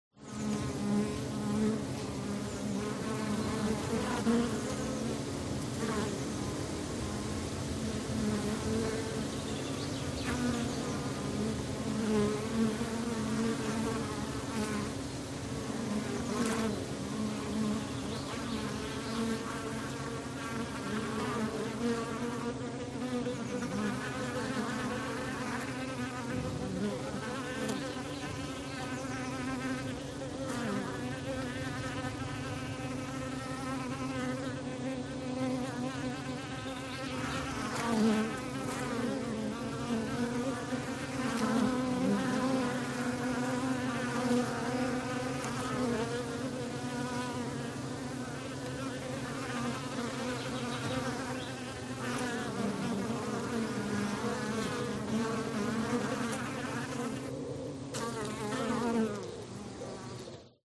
Odgłosy zwierząt wiejskich
Pszczoła
smartsound_ATMO_NATURE_Countryside_Bees_Wind_01.mp3